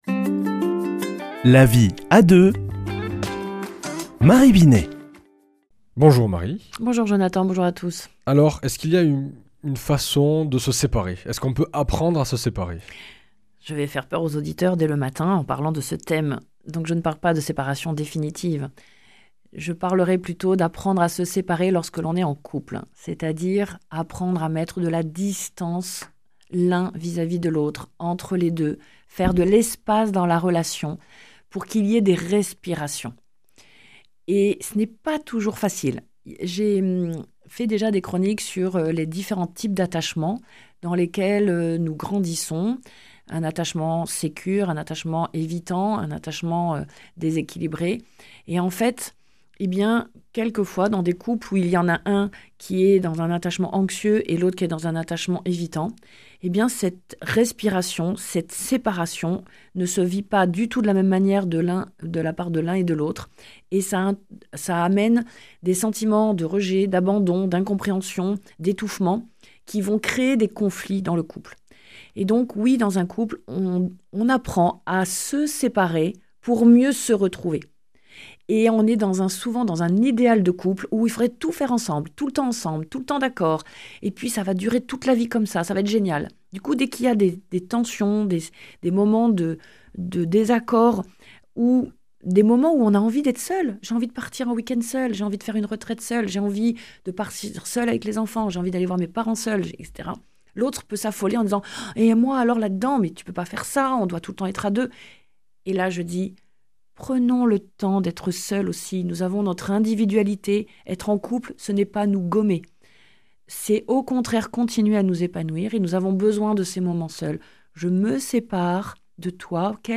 mardi 7 janvier 2025 Chronique La vie à deux Durée 4 min
Une émission présentée par